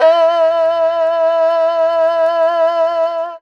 52-bi04-erhu-f-e3.wav